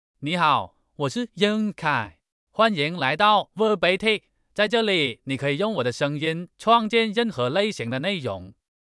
Yunqi — Male Chinese (Guangxi Accent Mandarin, Simplified) AI Voice | TTS, Voice Cloning & Video | Verbatik AI
MaleChinese (Guangxi Accent Mandarin, Simplified)
Yunqi — Male Chinese AI voice
Voice sample
Listen to Yunqi's male Chinese voice.